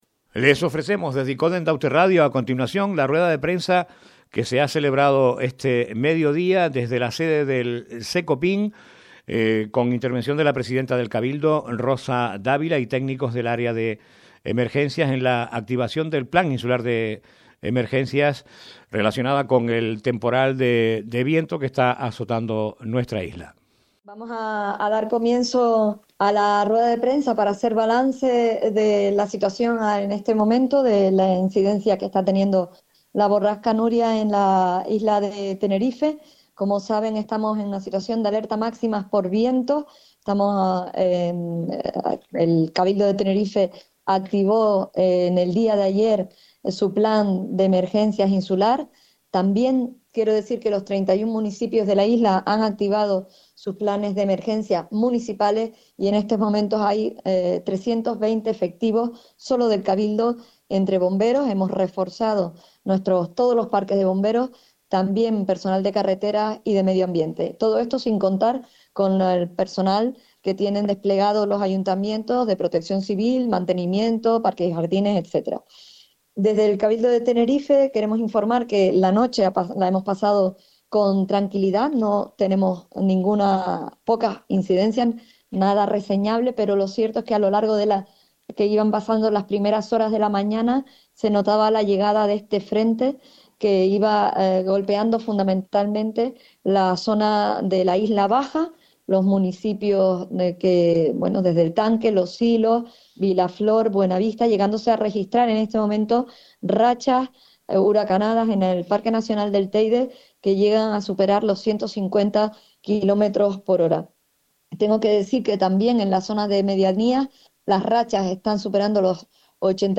YDR_RPRENSA_CECOPIN_CABILDO_TEMPORAL_VIENTO_mediodia_3_abril2025.mp3